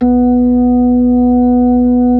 Index of /90_sSampleCDs/Keyboards of The 60's and 70's - CD2/B-3_Club Slow/B-3_Club Slow